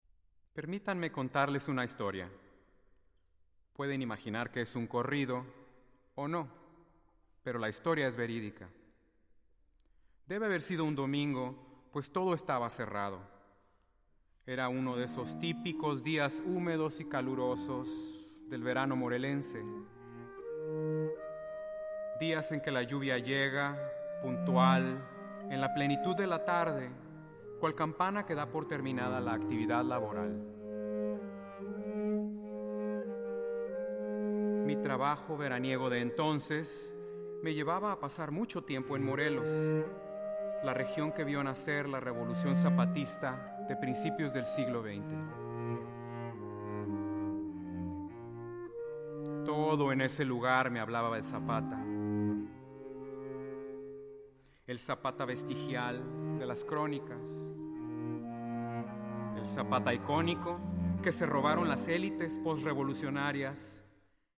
clarinet
cello